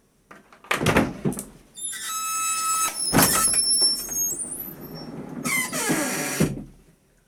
Bajar un mueble cama
bisagra
chasquido
chirrido
rechinar
Sonidos: Acciones humanas
Sonidos: Hogar